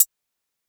TS HiHat_14.wav